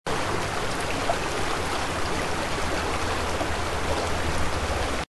Шум горной реки